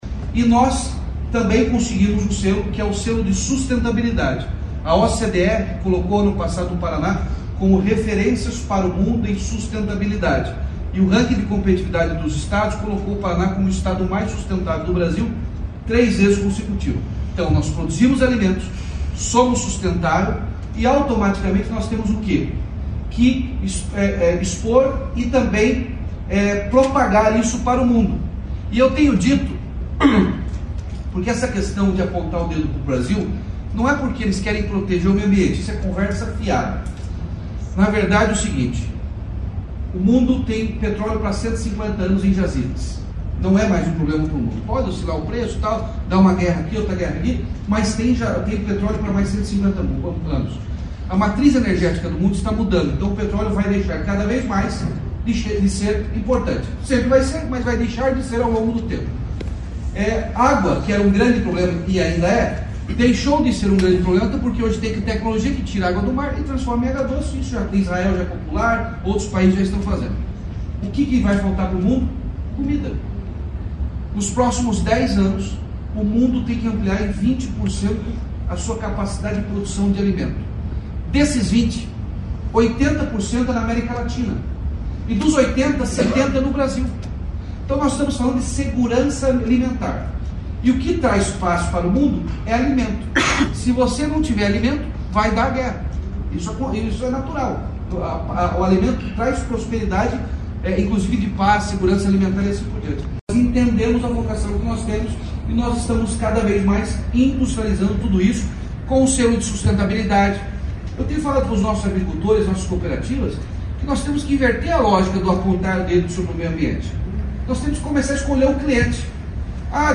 Sonora do governador Ratinho Junior no evento Gri Agro, em São Paulo, sobre a preparação da agricultura parananense para a produção de alimentos global